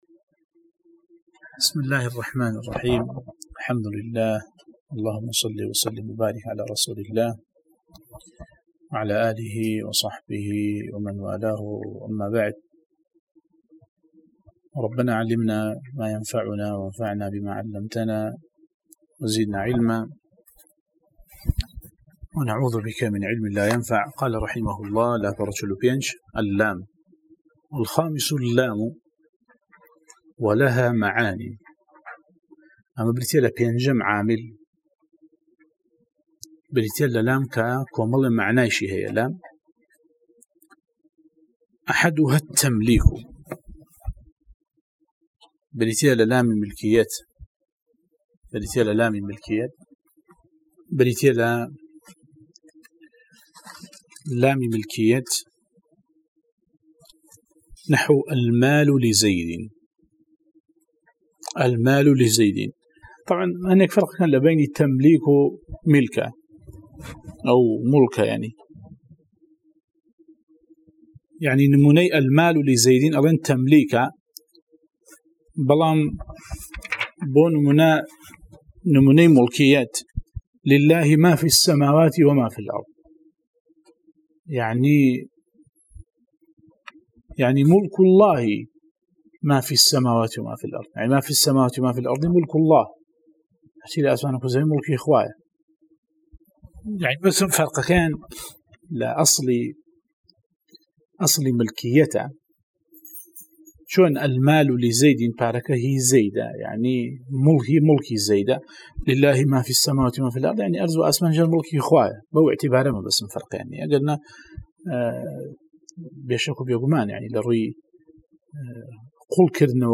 04 ـ شەرحی العوامل المائة، (عوامل الجرجانی) (نوێ) وانەی دەنگی: